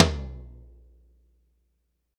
Index of /90_sSampleCDs/ILIO - Double Platinum Drums 1/CD4/Partition E/GRETSCH TOMD